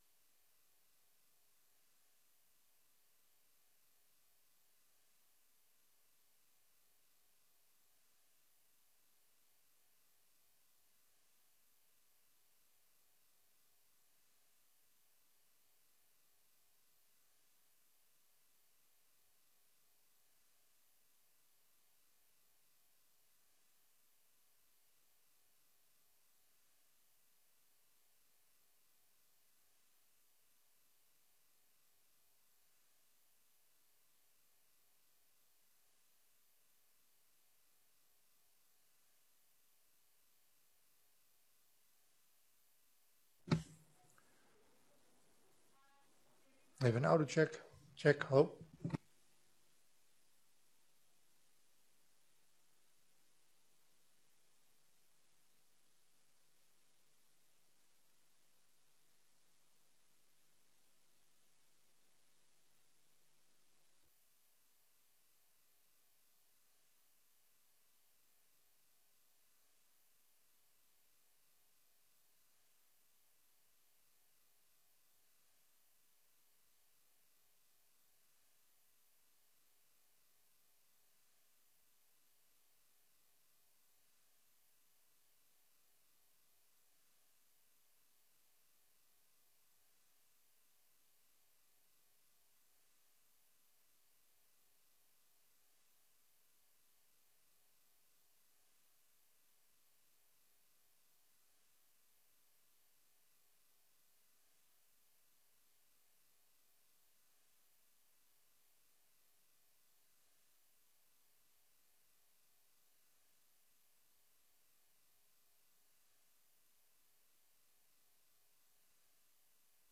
Politieke woensdag (Dag agenda) 10 maart 2021 10:00:00, Gemeente Groningen
Download de volledige audio van deze vergadering